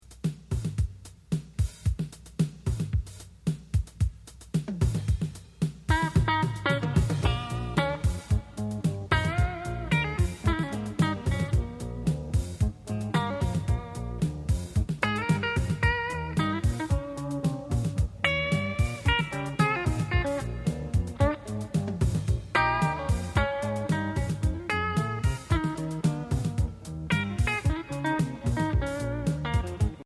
Voicing: Guitar Tab